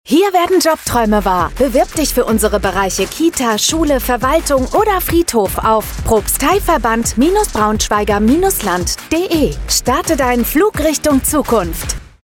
Ein Radiospot wirbt für den Ev.-luth. Propsteiverband Braunschweiger Land als Arbeitgeber
Vom 27. Mai bis 23. Juni präsentiert sich der Ev.-luth. Propsteiverband Braunschweiger Land auf Radio Antenne Niedersachsen zweimal täglich mit einem kurzen Werbespot als Arbeitgeber in der Region.
Funkspot_Ev.-luth._Propsteiverband_Braunschweiger_Land__12_Sek_.mp3